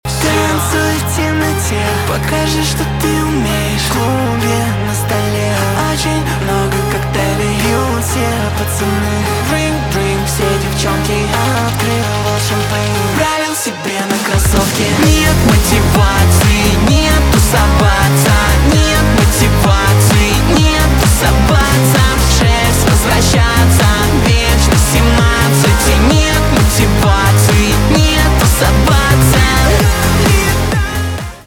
поп
танцевальные , битовые , басы